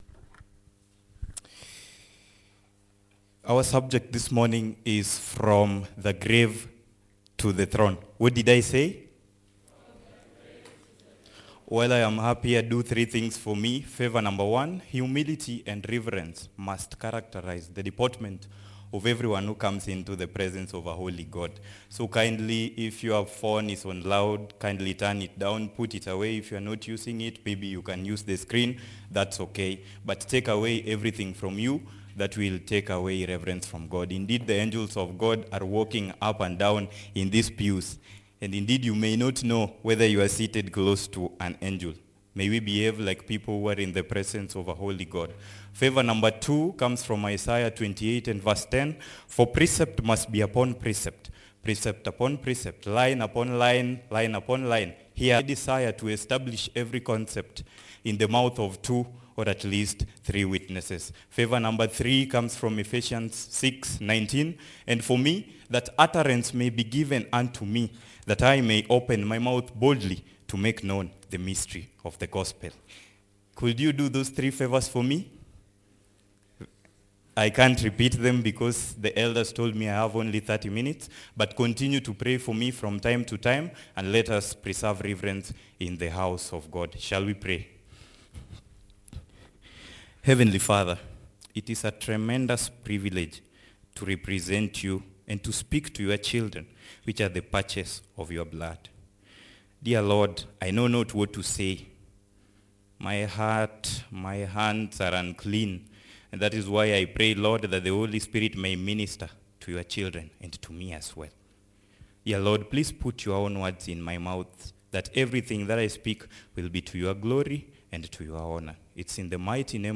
Tuksda Church -Sermons